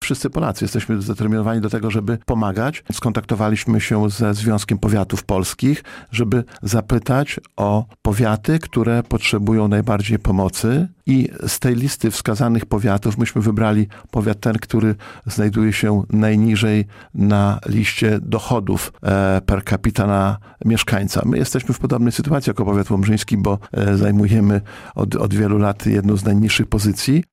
Starosta Lech Marek Szabłowski na antenie Radia Nadzieja podkreślał, że samorząd chce wspierać tych w najtrudniejszej sytuacji: